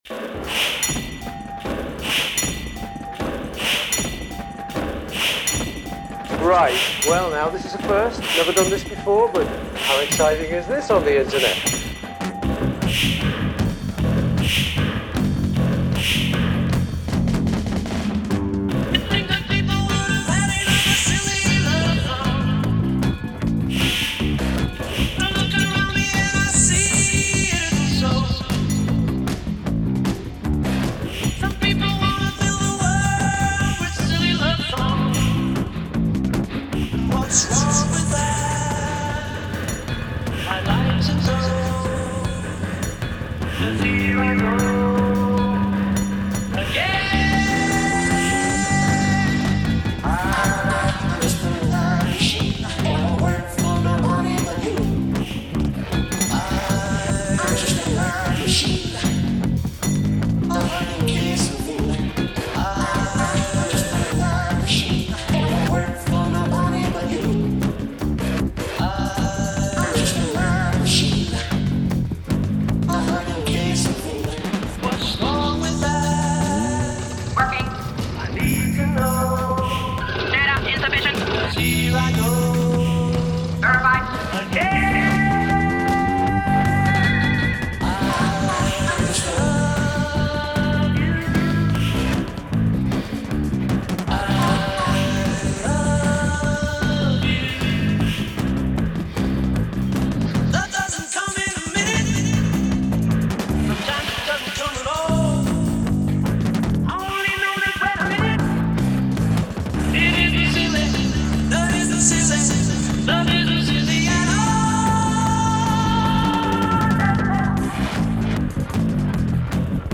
Remix/mashup inspired by the original intro: